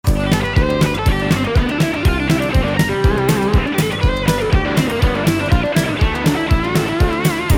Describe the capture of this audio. Nor do we know what kinds of guitars were used…We only know that the guitar tracks were recorded direct using only a SansAmp Classic and standard studio outboard EQ and reverb equipment.